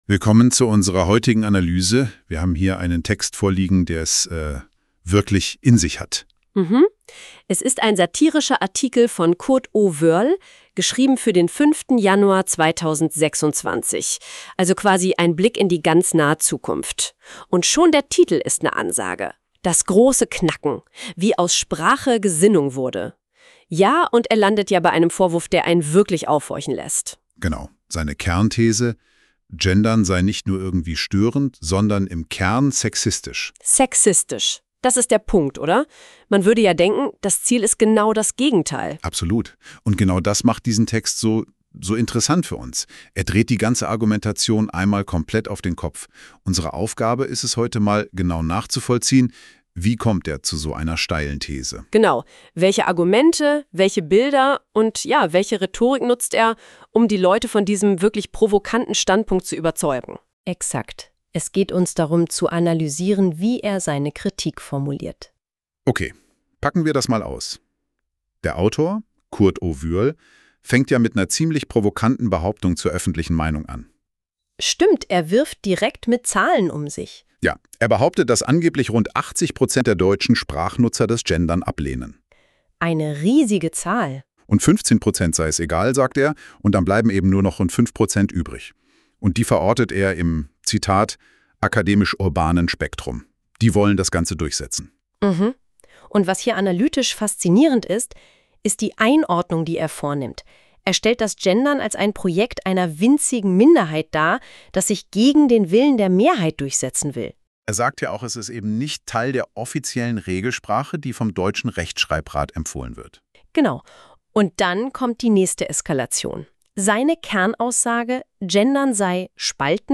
Plauderei zum Thema Podcast: Das große Knacken Wider dem Genderunfug Es gibt Dinge, die erledigen sich von selbst.